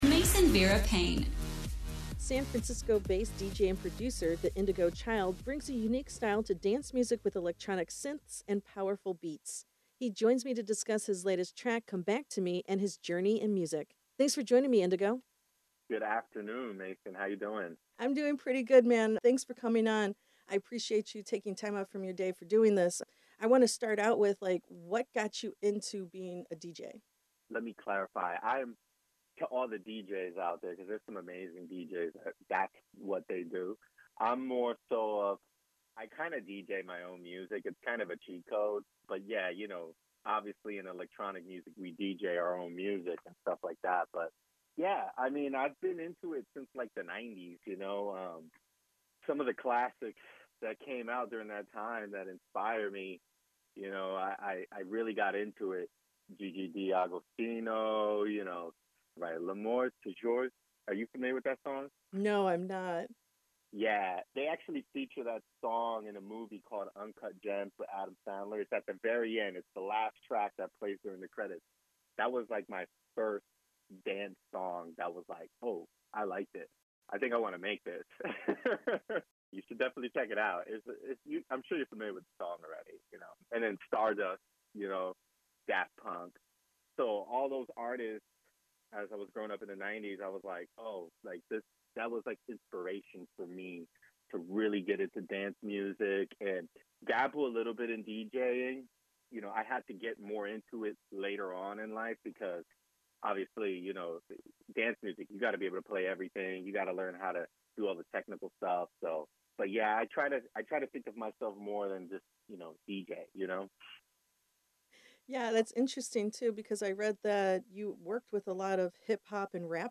His sound consists of a mix of electronic synths and strong beats.
Interview Transcription